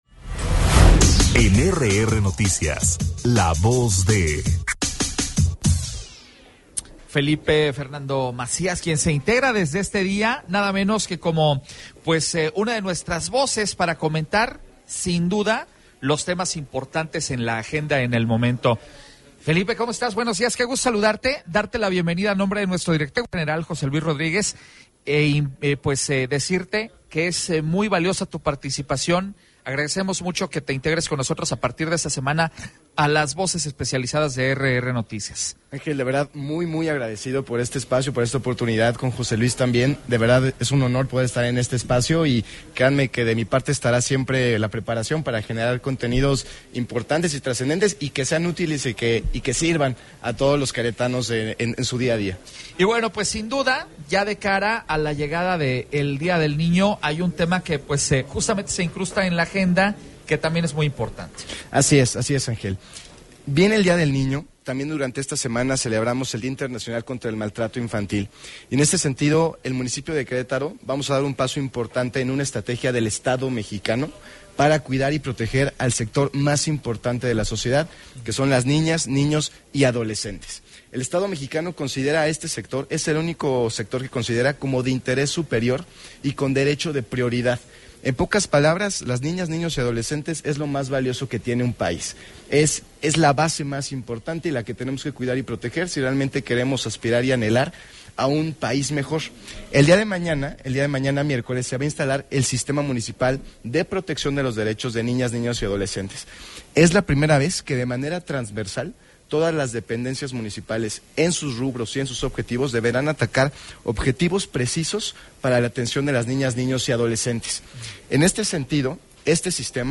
El regidor Felipe Fernando Macías habla sobre las acciones para proteger a niñas y niños en Querétaro